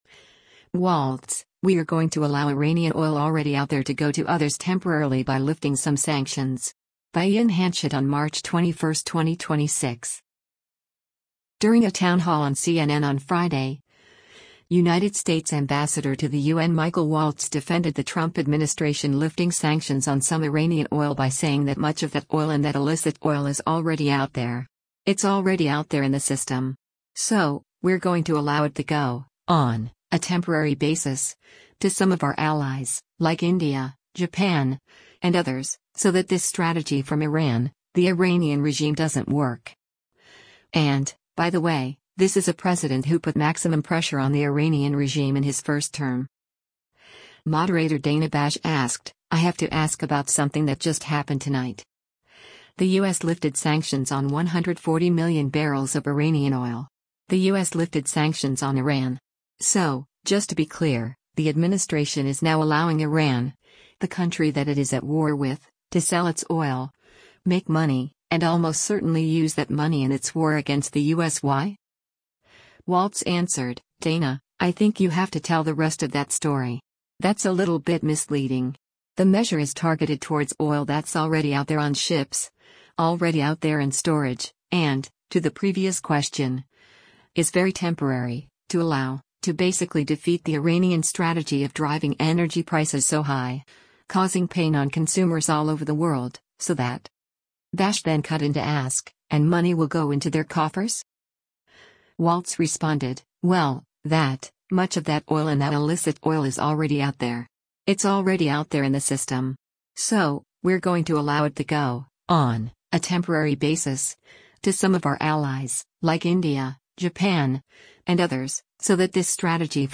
Bash then cut in to ask, “And money will go into their coffers?”